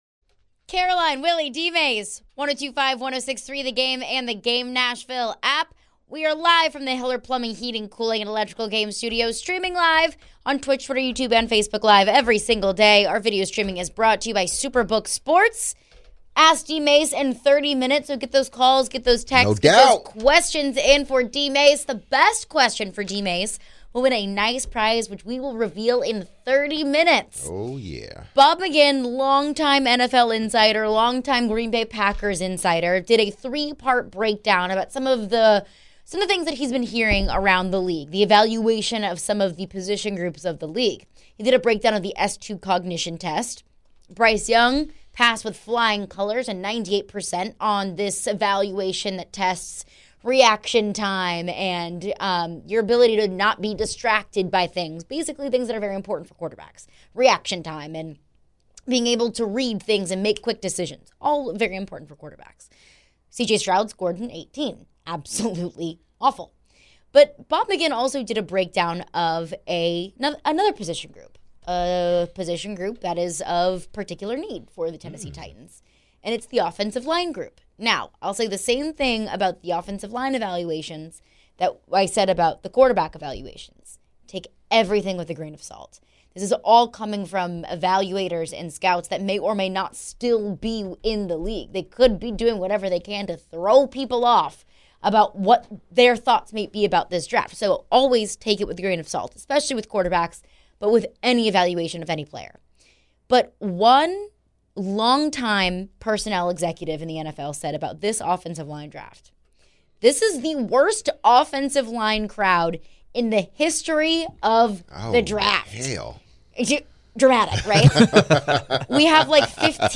interacting with our callers & texters.